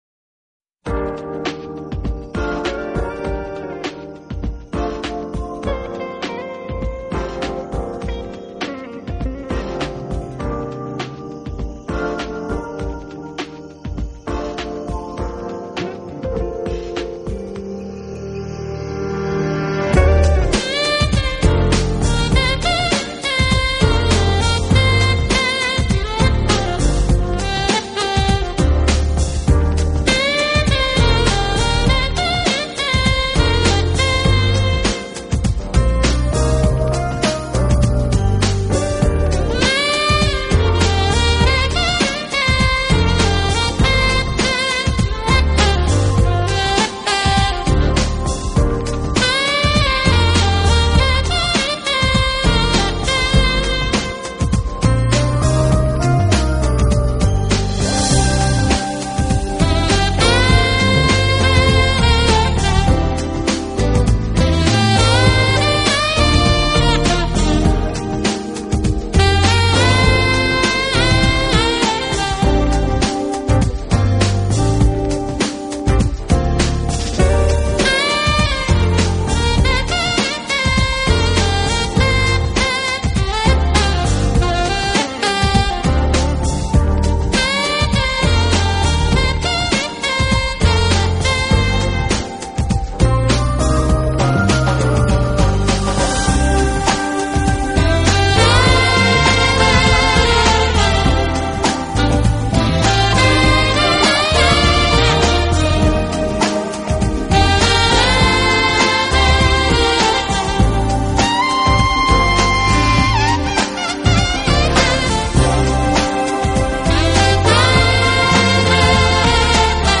【爵士萨克斯】
这是很有源味的多音域SAX，是长、短笛，是他的音乐。